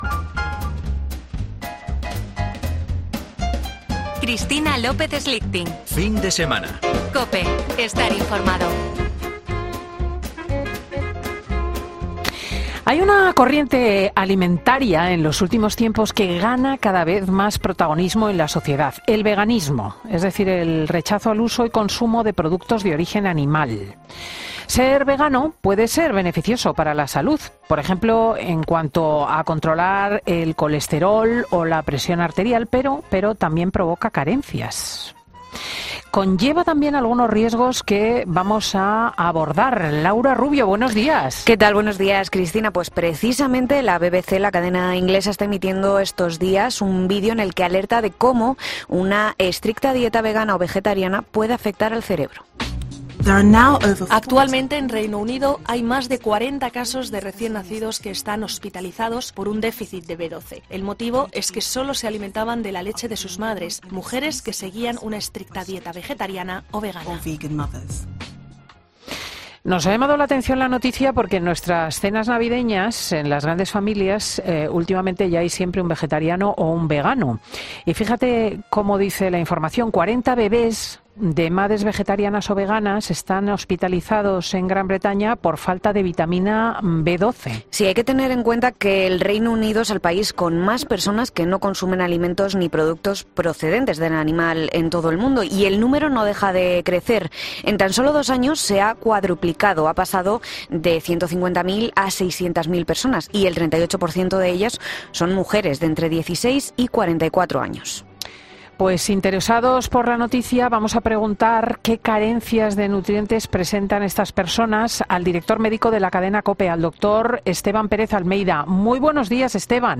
Para saber cuáles son, hemos hablado con nuestro médico de cabecera